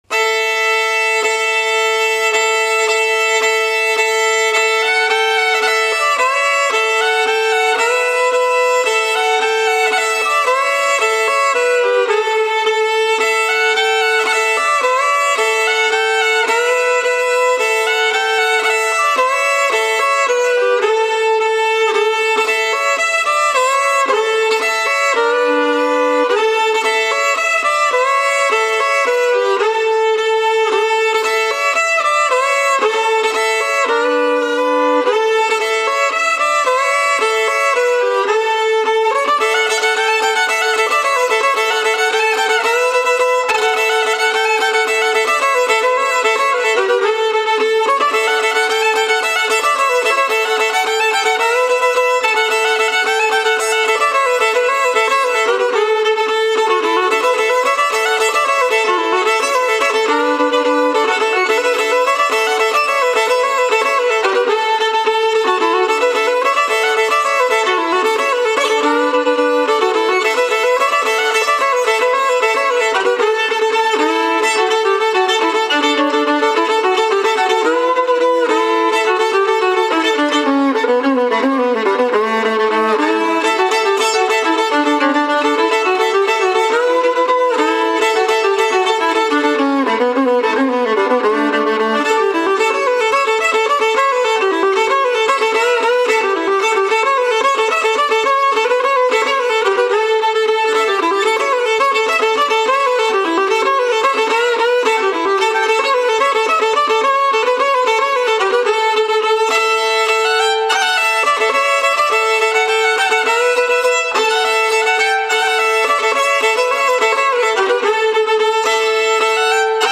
I have played the violin since I was 8 years old.
Here is me playing Old Joe Clark, an american fiddle tune. That recording was done in the basement of a friend.